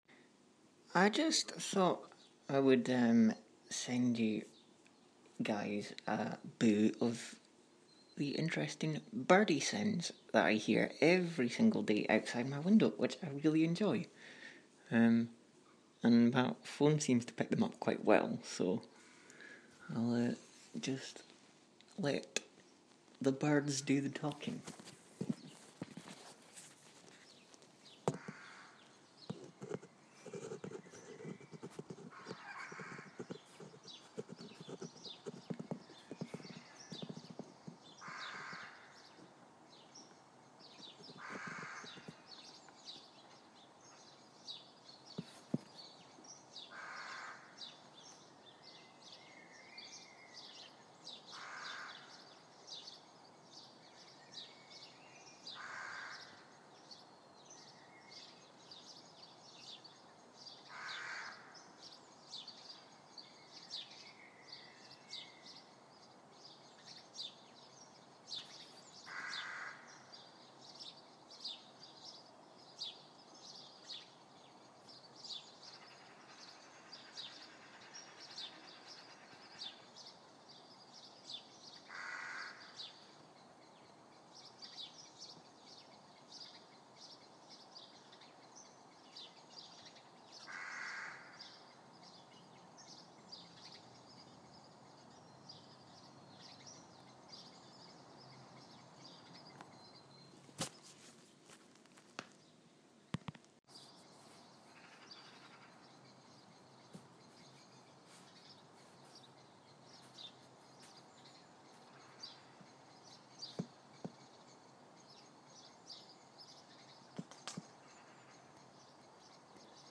Tweeting talk live from Edinburgh